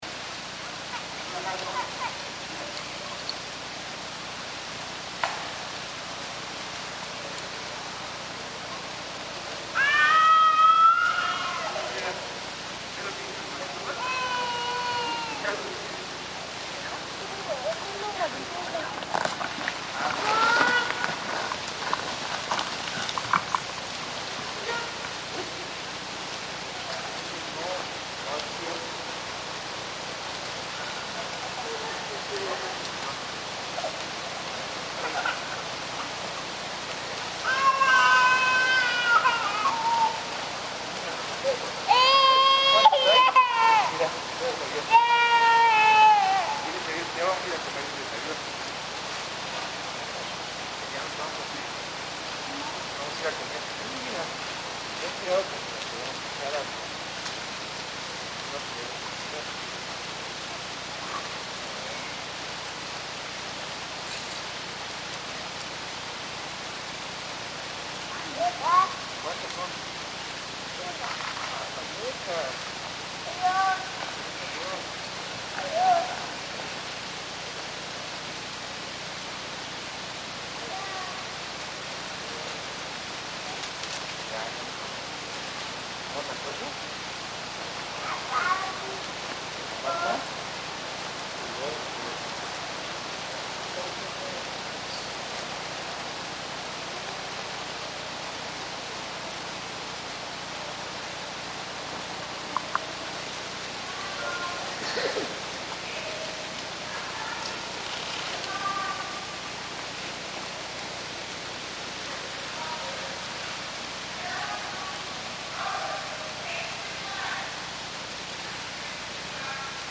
Monarch butterfly sanctuary, El Rosario. Stereo 48kHz 24bit.